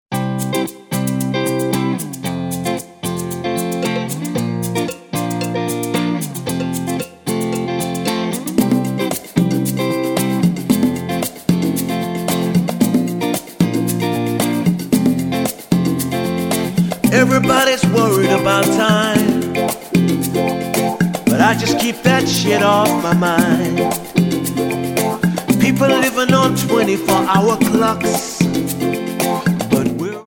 --> MP3 Demo abspielen...
Tonart:F Multifile (kein Sofortdownload.
Die besten Playbacks Instrumentals und Karaoke Versionen .